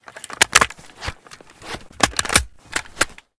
aks74u_reload.ogg